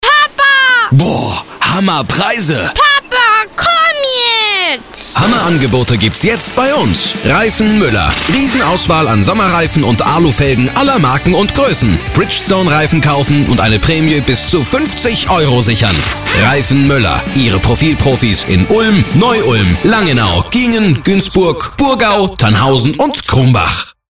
Wandelbare, freundliche und angenehme Männerstimme mittleren Alters.
Sprechprobe: Werbung (Muttersprache):